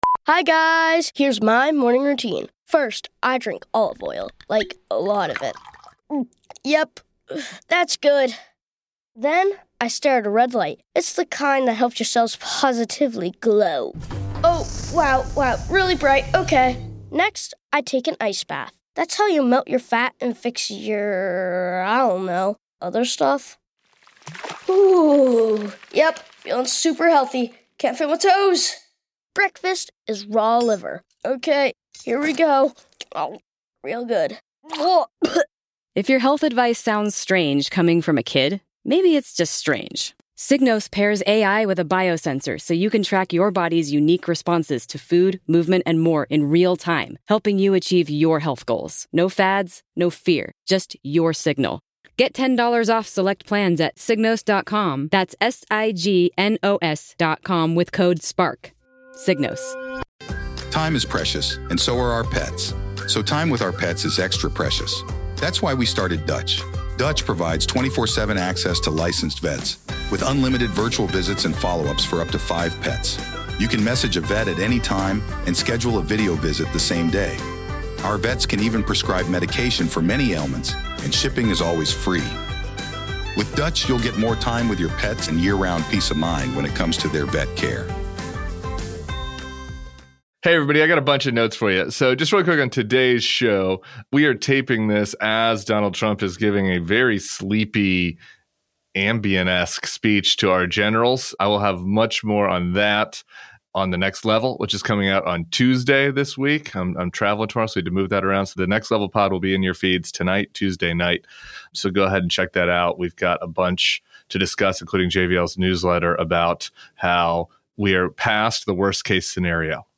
David Jolly, a Democratic gubernatorial candidate in Florida, joins Tim to talk about his campaign, why he switched parties and how he thinks he can reverse his state’s political trajectory. Then, The Ringer’s Van Lathan talks with Tim about our recent interview with Ezra Klein and what political conversations about beating MAGA are missing.